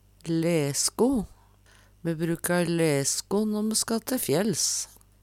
Tilleggsopplysningar "lærsko" blir og bruka See also hu (Veggli) Hør på dette ordet